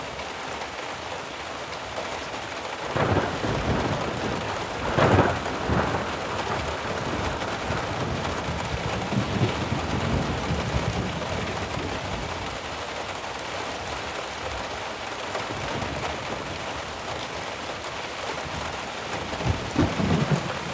Ambiance Orage (Broadcast) – Le Studio JeeeP Prod
Bruits d’ambiance au cours d’un orage qui gronde.
Orage20.mp3